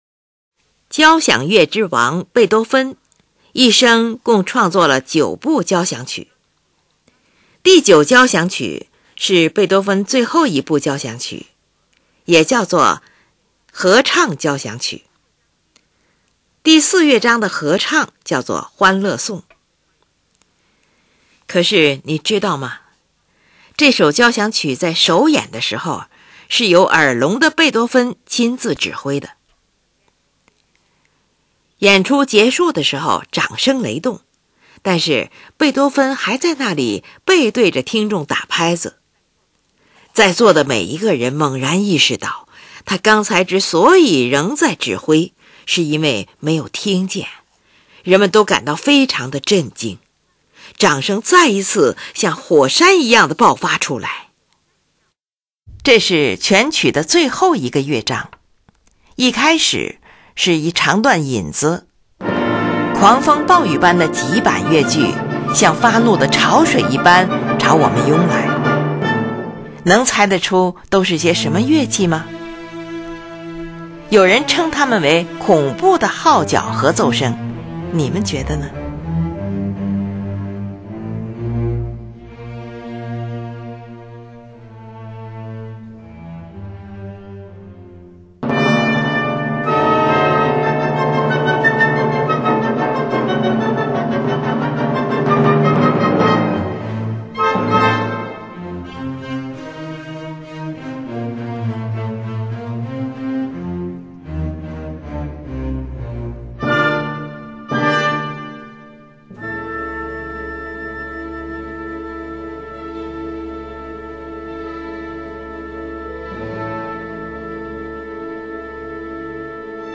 第四乐章，急板，D大调，4/4拍。
这个序奏部分是坚强刚毅，惊心动魄的。
乐章的最后，这种气氛被表现到了极致，整部作品在无比光明、无比辉煌的情景下结束。